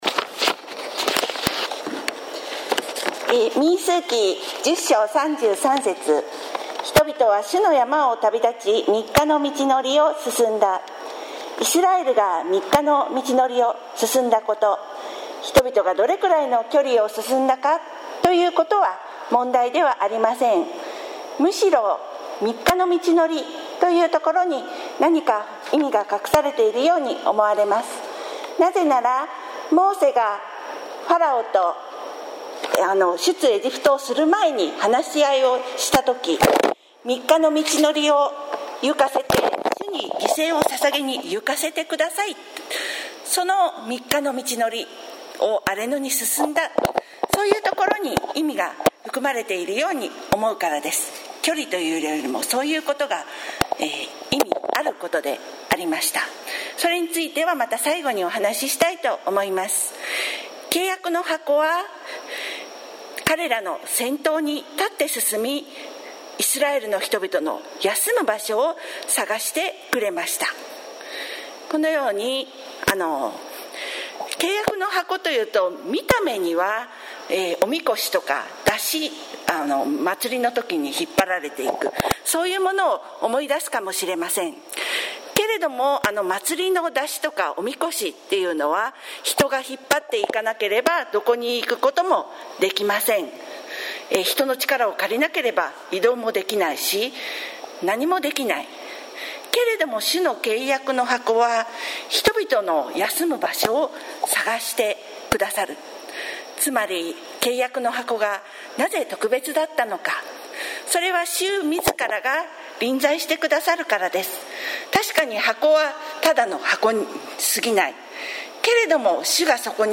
sermon-2020-09-13